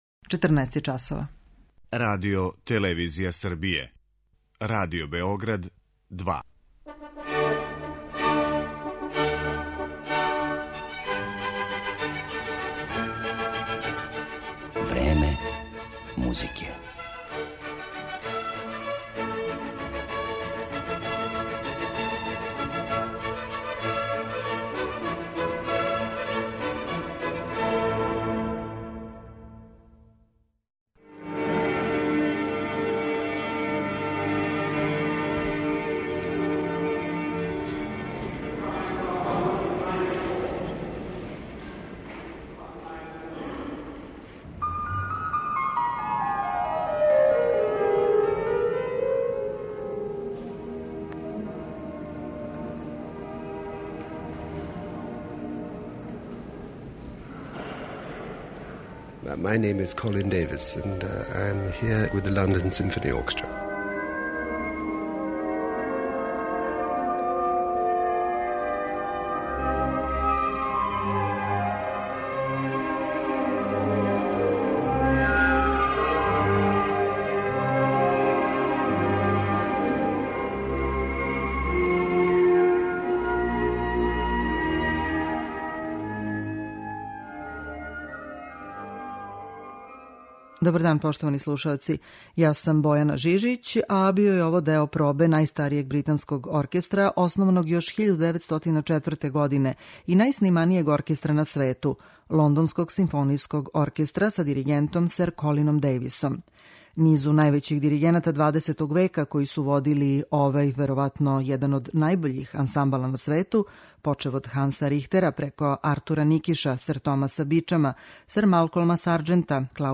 Једном од највећих музичара нашег доба и дугогодишњем руководиоцу Лондонског симфонијског оркестра, британском диригенту сер Колину Дејвису, посвећена је данашња емисија у којој ћете моћи да чујете и интервју са овим славним уметником.